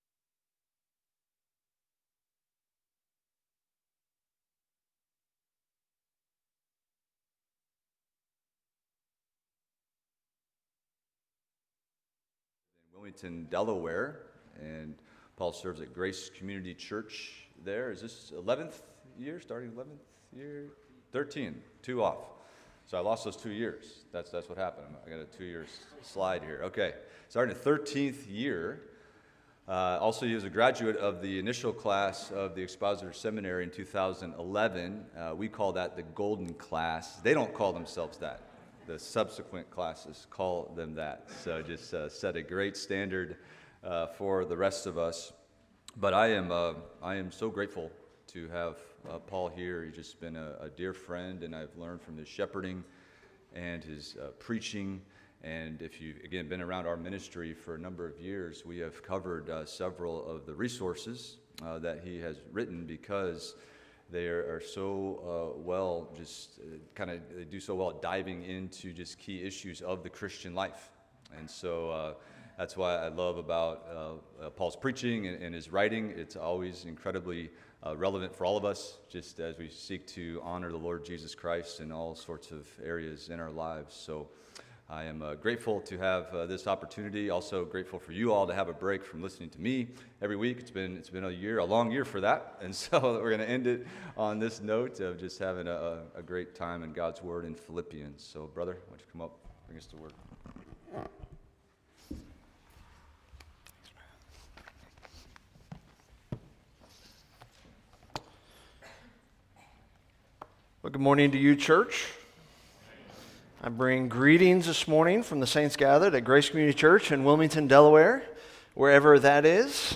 Expository Preaching from Philippians – Philippians 2:12-13 – Work Out Your Salvation